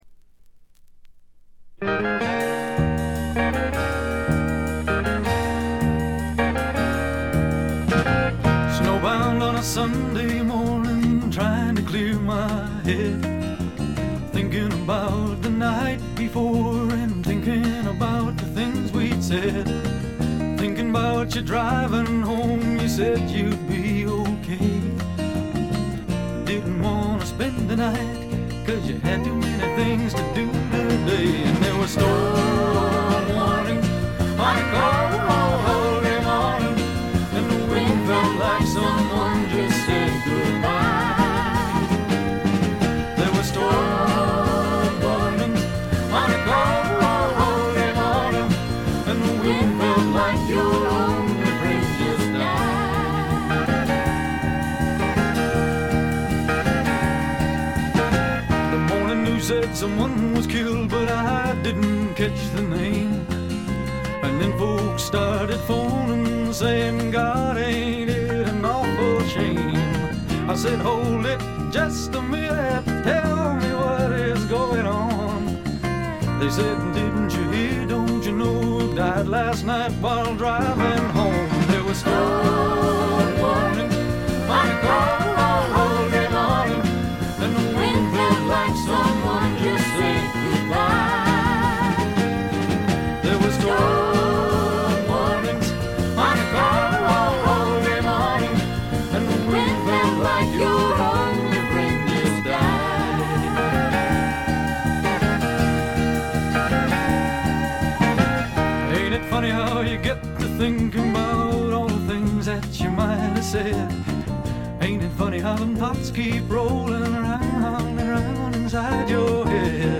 カナディアンらしくカントリー臭とか土臭さはあまり感じません。
試聴曲は現品からの取り込み音源です。